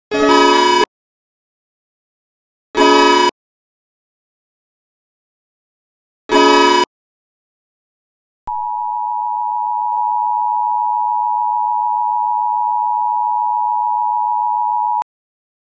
Bhutan Eas Alarm (1949) Sound Button - Free Download & Play